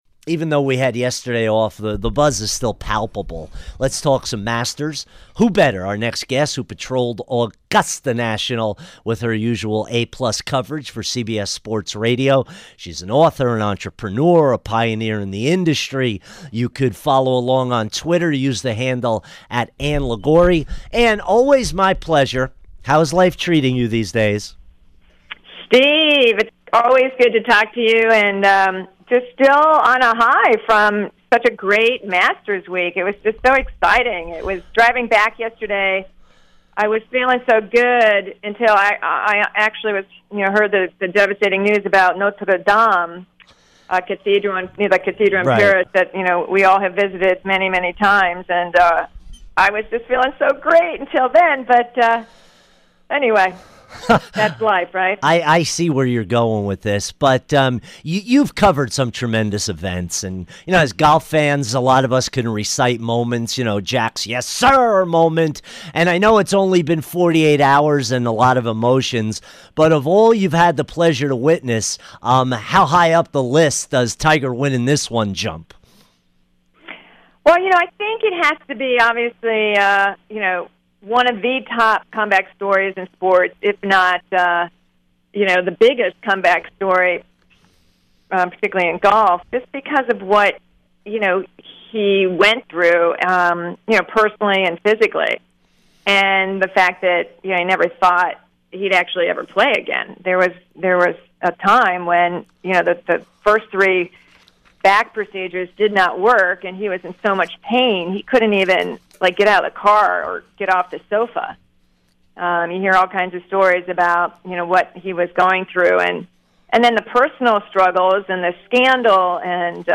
Sports Talk - Ann Liguori Interview 4-16-19